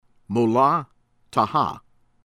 SPANTA, RANGEEN DADFAR ran-GHEEN   dad-FAHR   SPEHN-tah